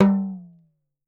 03 TALKING D.wav